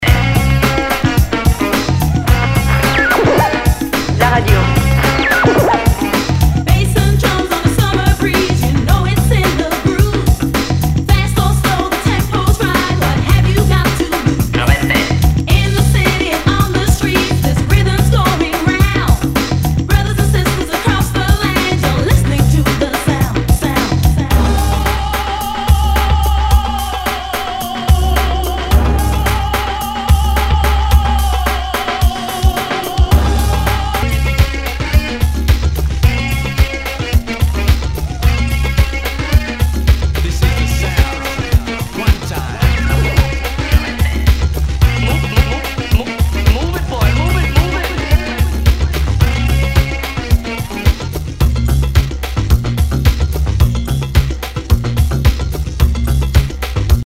HOUSE/TECHNO/ELECTRO
UKハウス・クラシック！